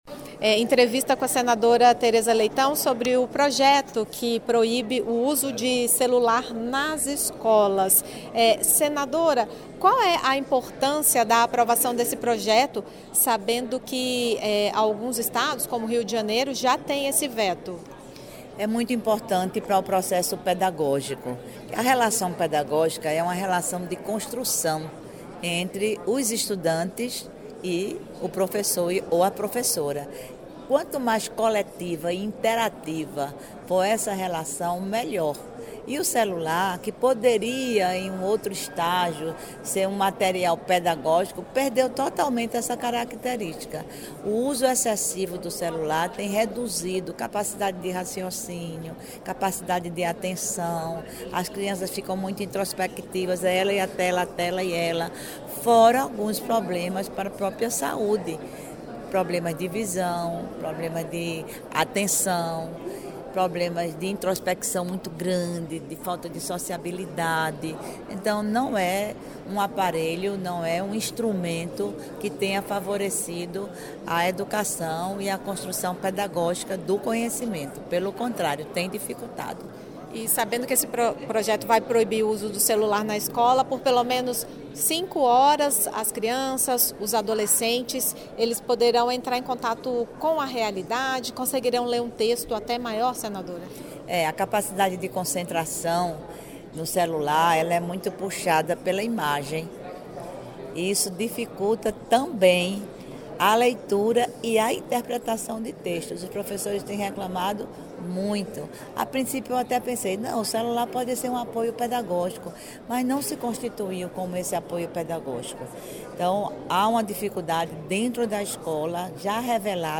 Entrevista: Teresa Leitão comenta sobre uso do celular nas escolas